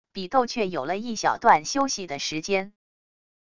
比斗却有了一小段休息的时间wav音频生成系统WAV Audio Player